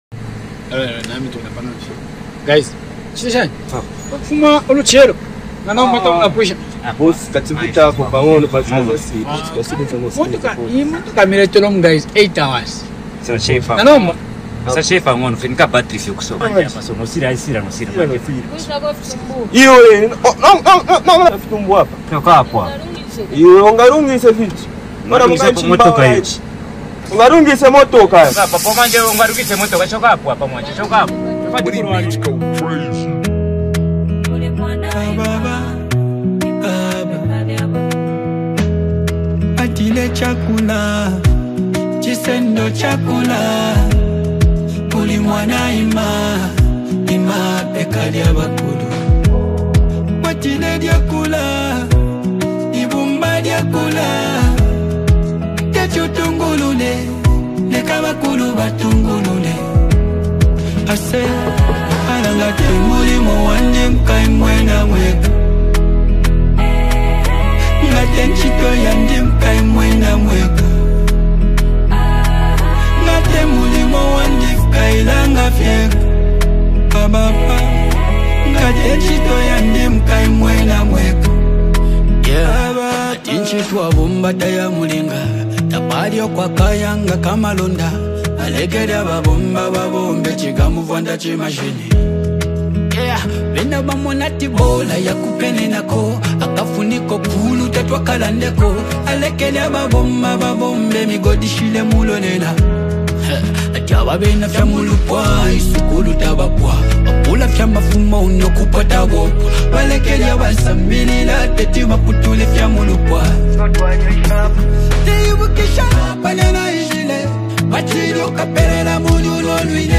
Known for his soulful vocals and thought-provoking lyrics
a heartfelt message wrapped in rich Zambian rhythms
Hip hop